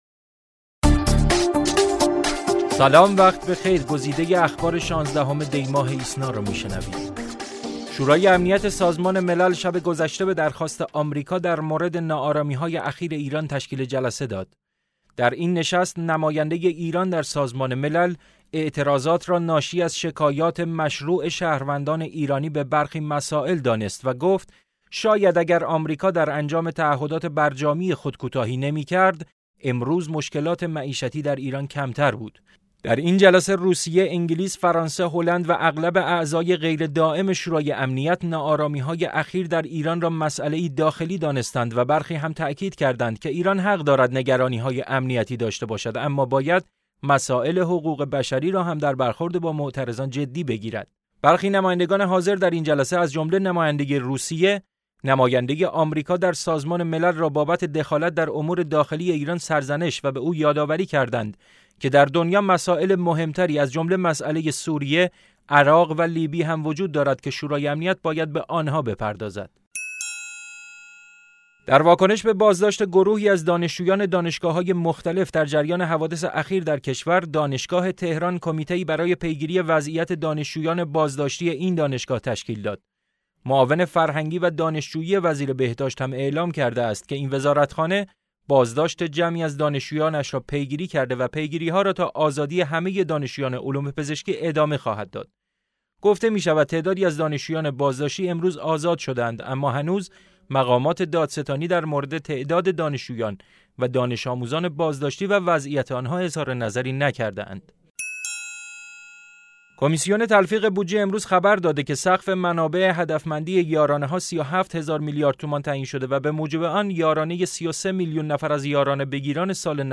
صوت / بسته خبری ۱۶ دی ۹۶